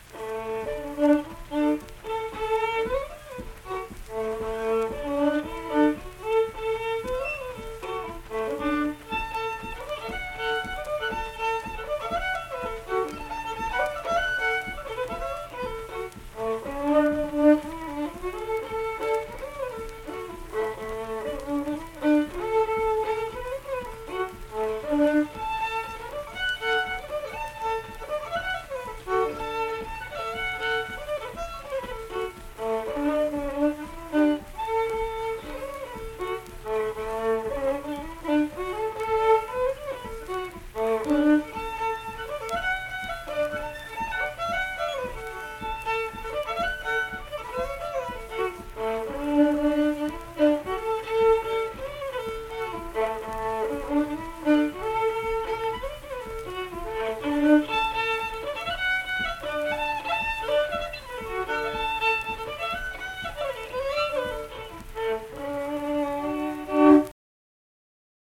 Unaccompanied fiddle performance
Instrumental Music
Fiddle
Middlebourne (W. Va.), Tyler County (W. Va.)